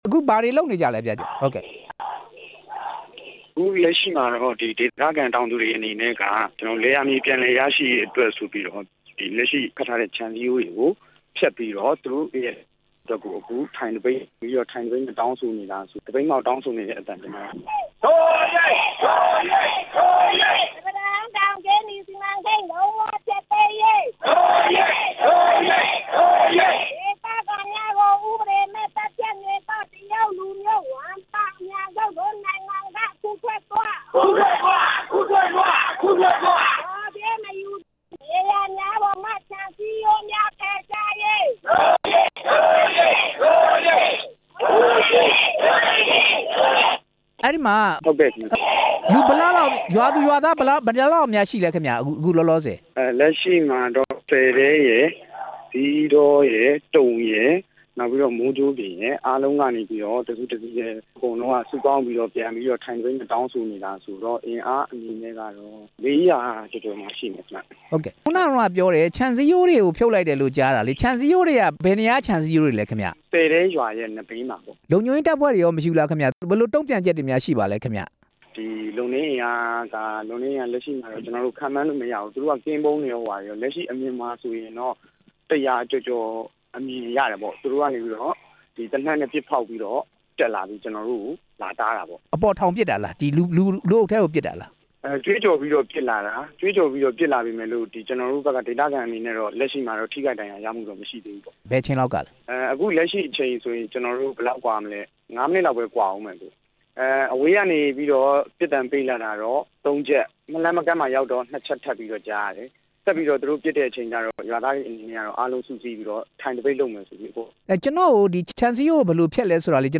သပိတ်မှောက်နေတဲ့ ဒေသခံ